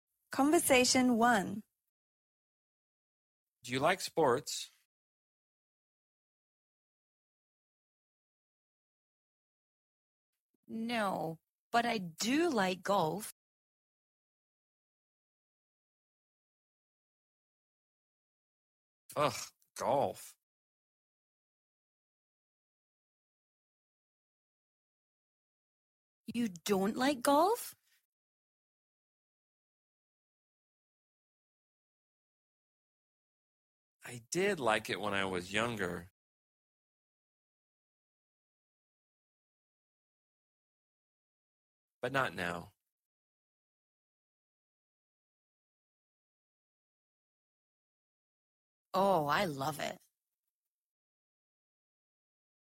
Speak and Peek - Shadowing  Play the audio below with short pauses.